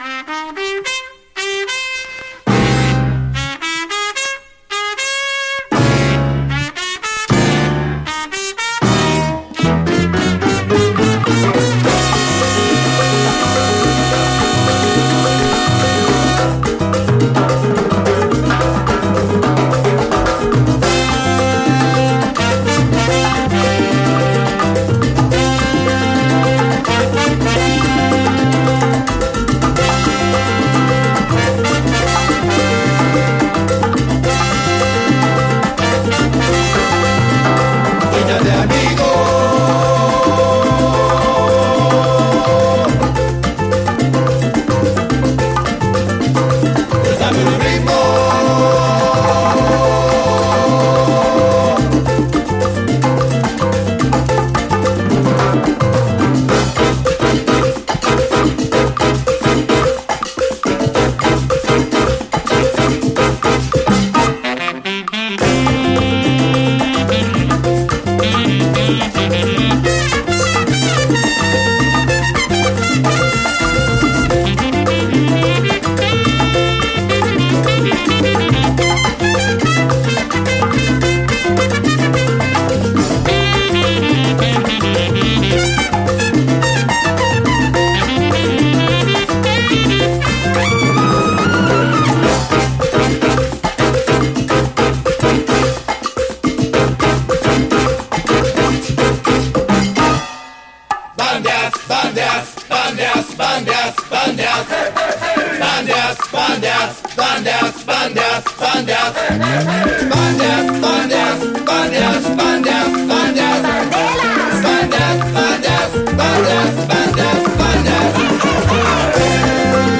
2016年の国産ラテン・ファンク/灼熱キラー・マンボ！